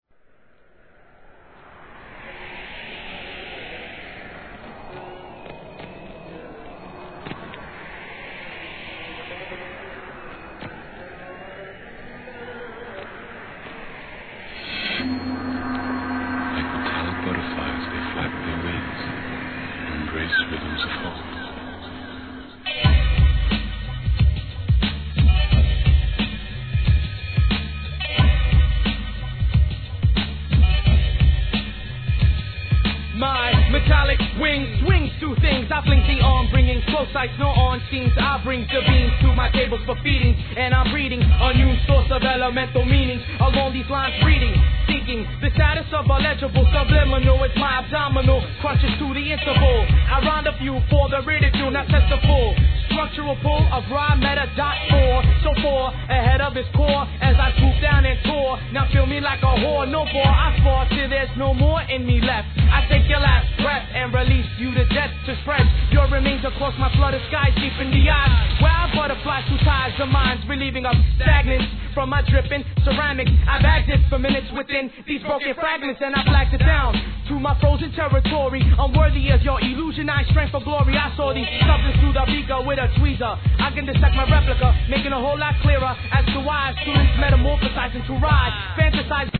レア・インディーHIP HOP!!